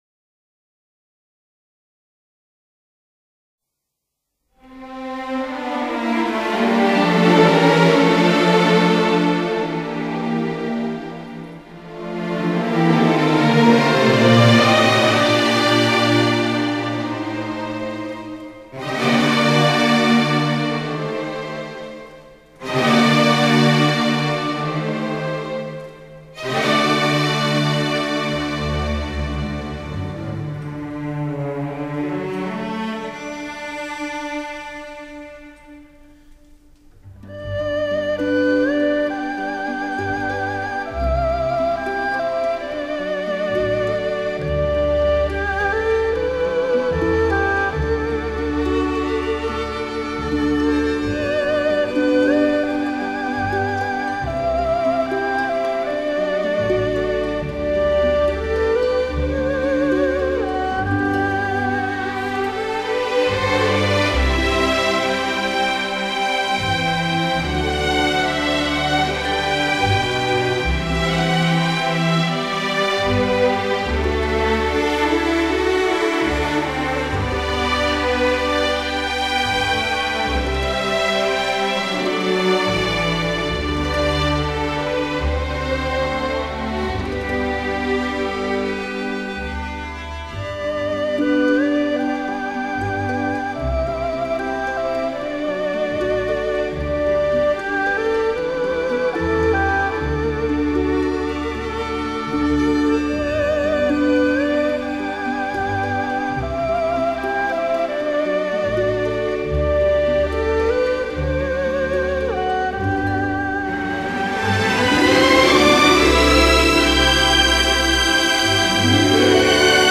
最具时代风貌歌曲管弦乐改编曲